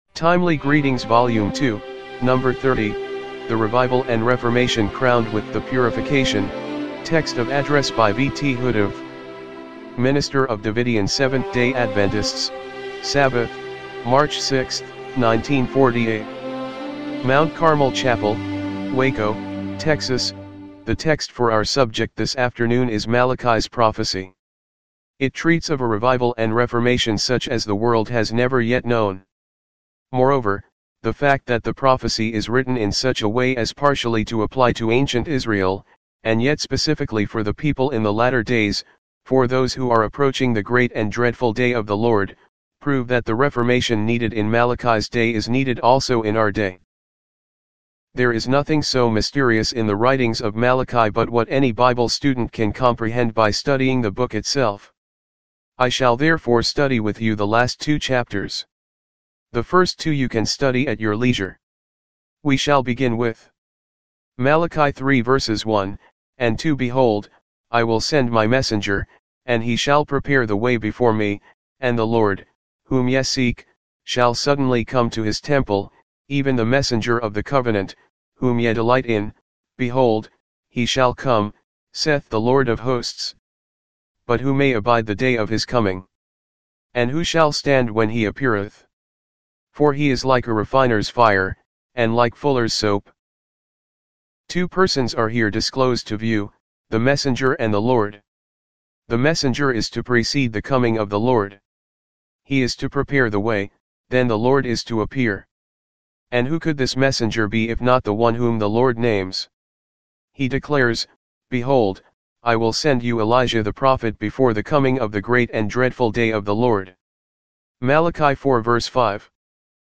MINISTER OF DAVIDIAN 7TH-DAY ADVENTISTS SABBATH, MARCH 6, 1948 MT. CARMEL CHAPEL WACO, TEXAS